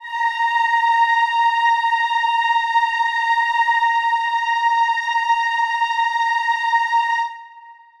Choir Piano (Wav)
A#5.wav